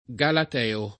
galat$o] pers. m. stor.